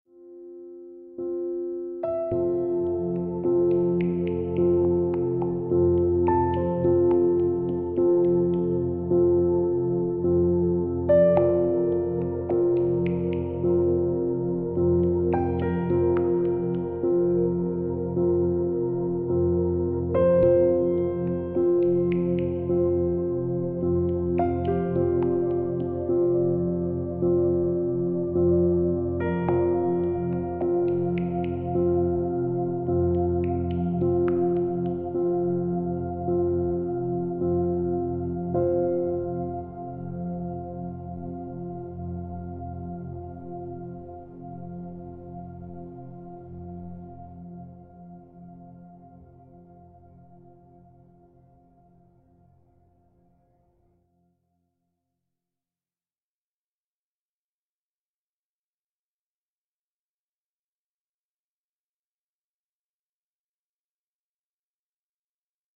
Von minimalistisch bis orchestral.